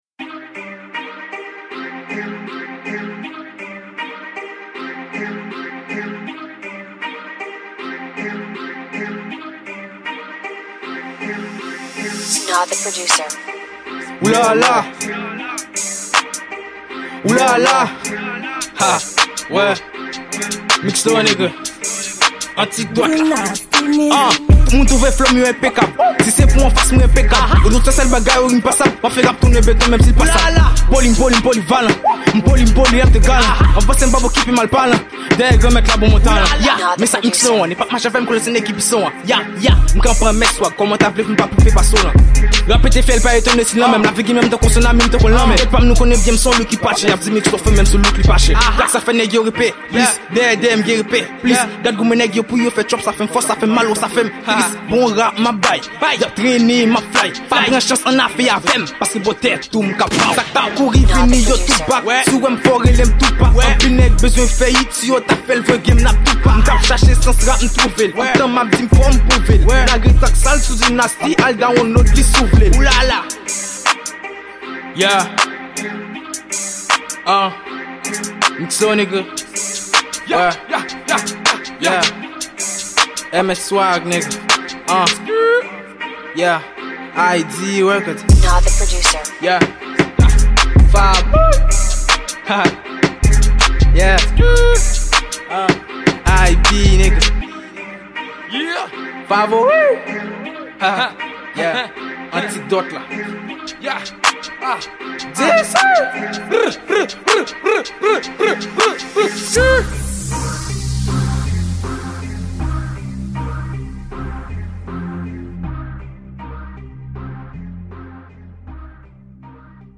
Genre: Rap